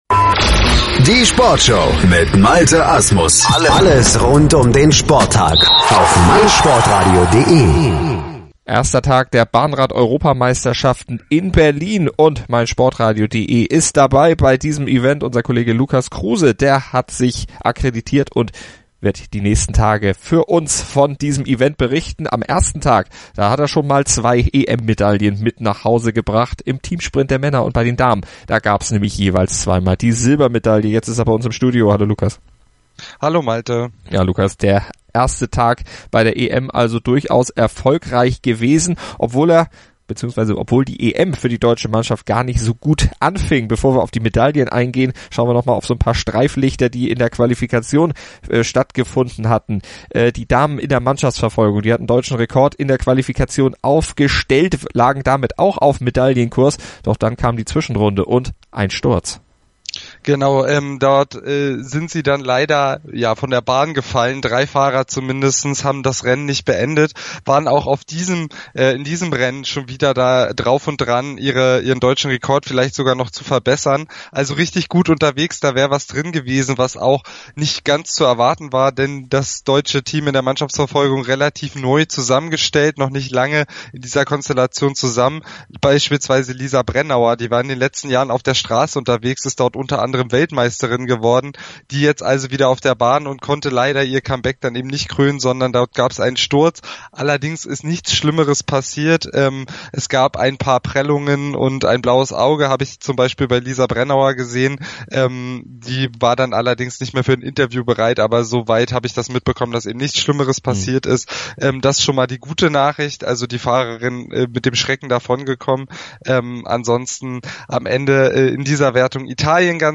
für uns vor Ort im Velodrome in Berlin